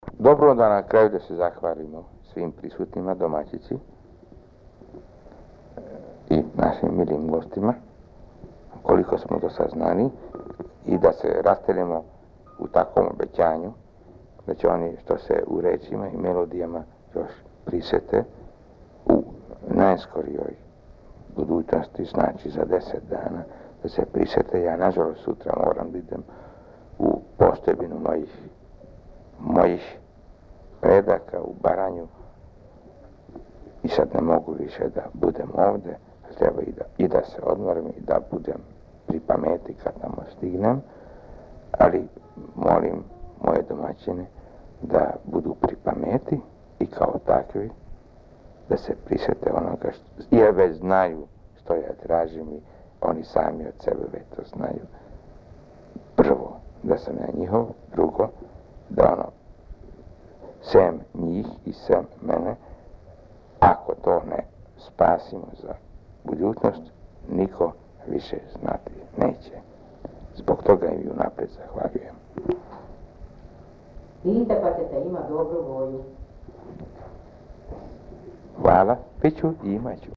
Варијетет: Д